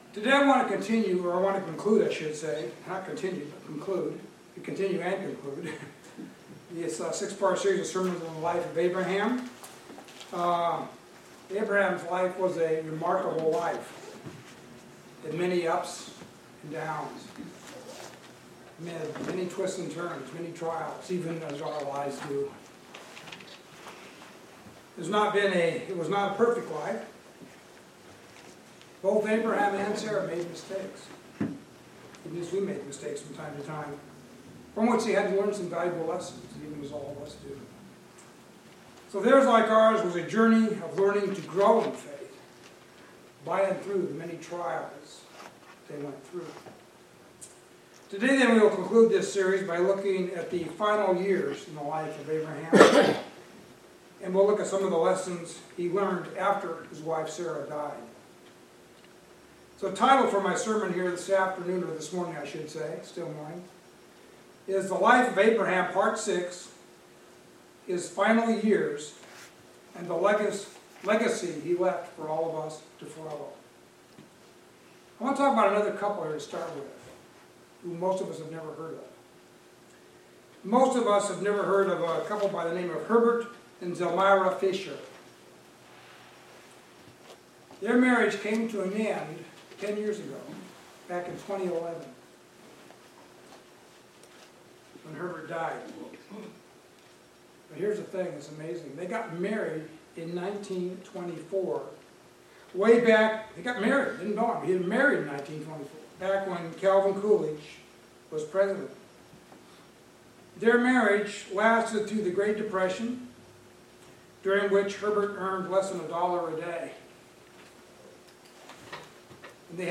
We will then conclude this series of sermons by looking at the legacy he left for all of us to follow and how that can help us in our journey of learning to live by faith as Abraham did.